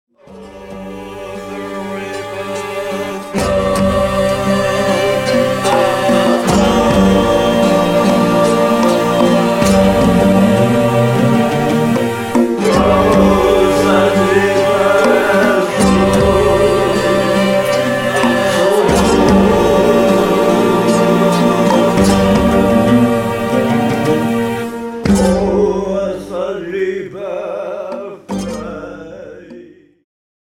ジャンル Progressive
シンフォニック系
ワールドミュージック
民族楽器とシンフォニック・ロックが絶妙に絡み合い異郷に誘う！
oud
acoustic guitar
tombak